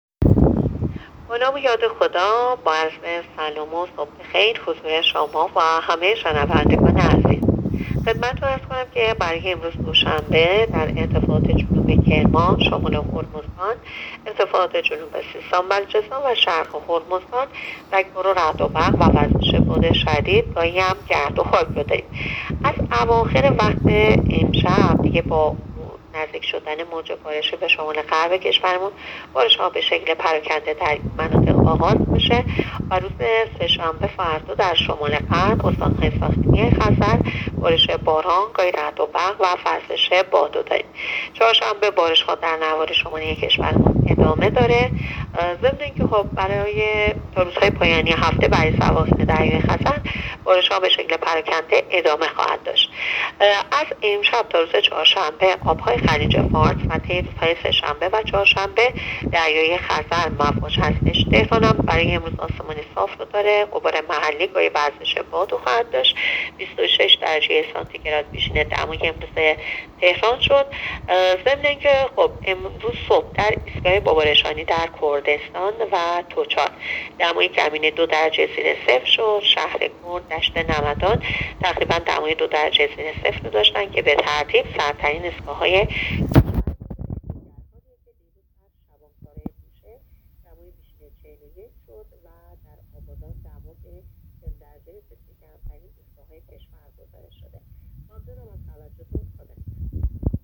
گزارش رادیو اینترنتی پایگاه‌ خبری از آخرین وضعیت آب‌وهوای ۲۸ مهر؛